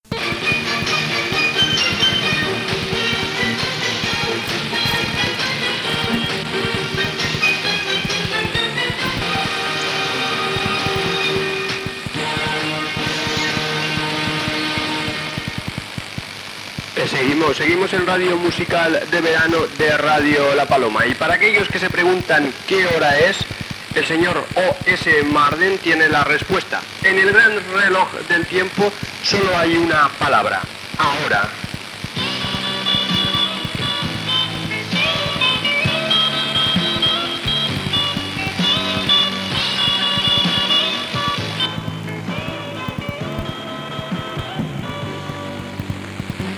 507e6d98a0f044e3f4c6140f293b91f93f21a5d0.mp3 Títol Radio La Paloma Emissora Radio La Paloma Titularitat Tercer sector Tercer sector Barri o districte Descripció Identificació de l'emissora i tema musical. Gènere radiofònic Musical